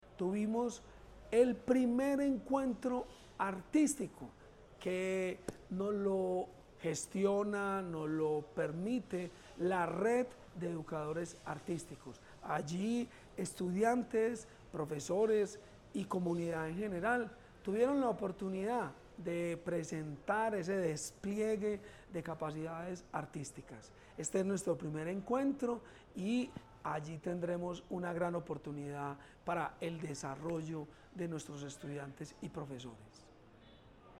Audio Declaraciones del subsecretario de la Prestación del Servicio Educativo, Jorge Iván Ríos El Centro de Innovación del Maestro, Mova, se transformó en un escenario de arte y cultura durante la segunda edición de la Semana de la Educación Artística y Cultural.
Audio-Declaraciones-del-subsecretario-de-la-Prestacion-del-Servicio-Educativo-Jorge-Ivan-Rios.mp3